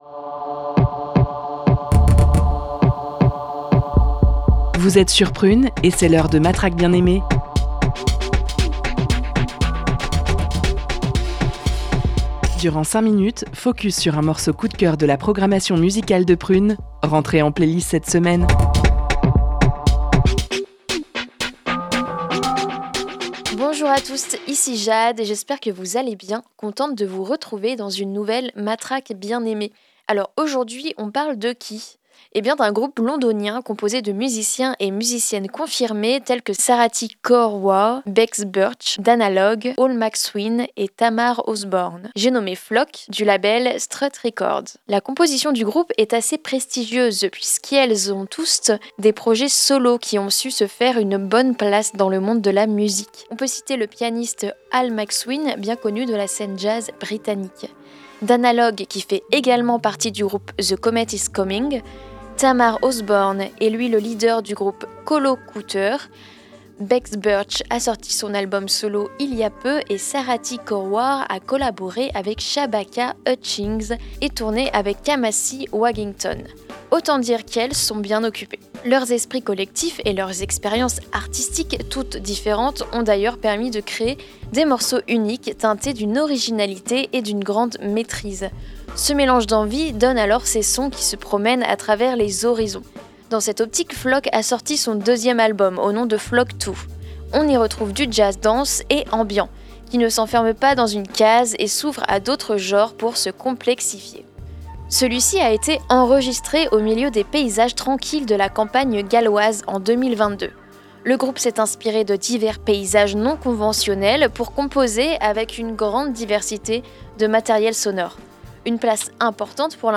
Composé de musiens et musiciennes confirmés
jazz dense et ambient